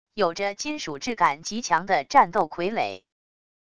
有着金属质感极强的战斗傀儡wav音频